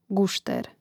gȕštēr gušter